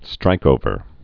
(strīkōvər)